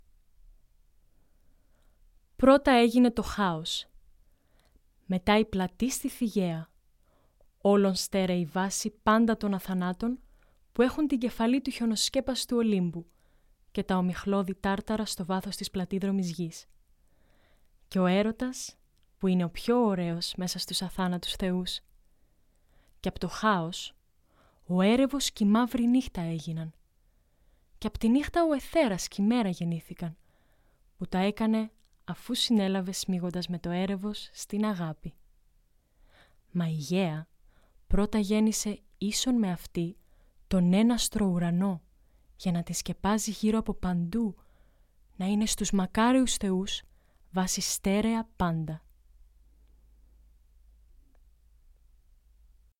Greek narrative poem
- Contralto